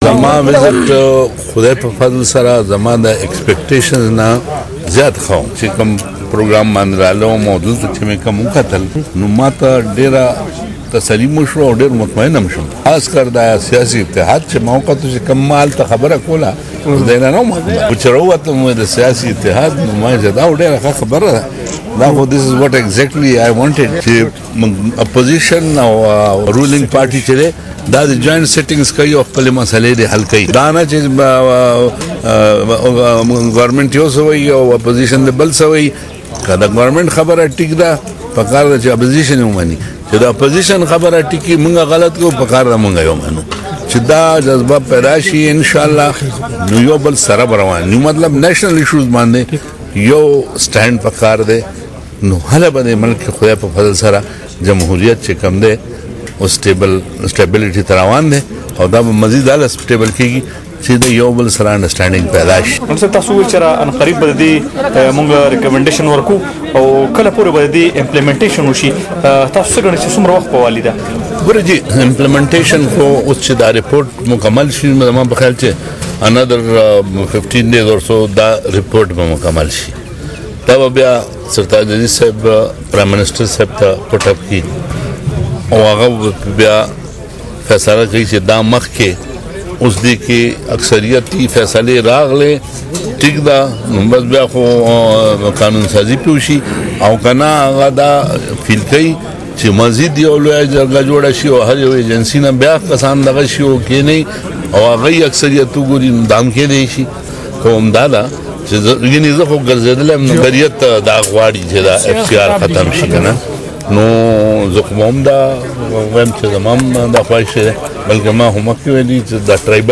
د گورنر ظفر اقبال جهگړا مرکه